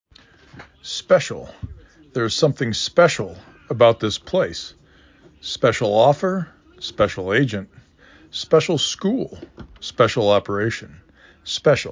7 Letters, 2 Syllables
'spe cial
s p e S ə l